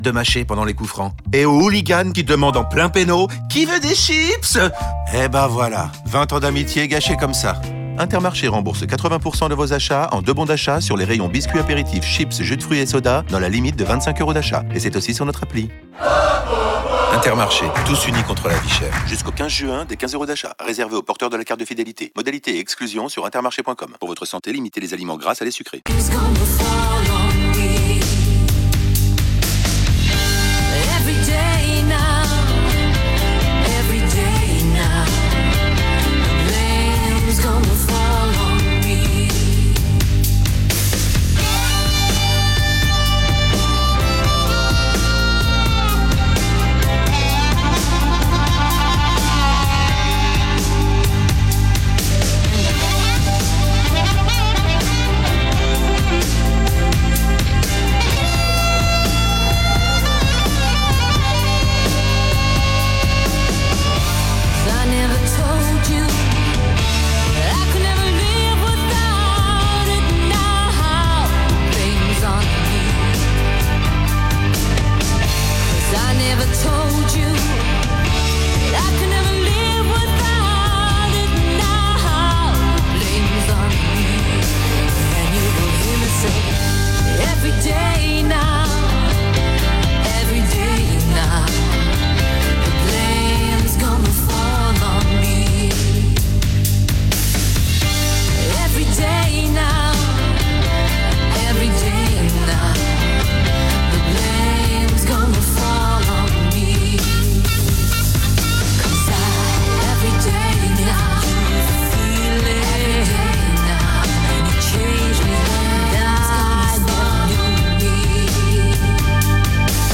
Journal du mercredi 11 juin (midi)